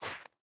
Minecraft Version Minecraft Version latest Latest Release | Latest Snapshot latest / assets / minecraft / sounds / block / powder_snow / break2.ogg Compare With Compare With Latest Release | Latest Snapshot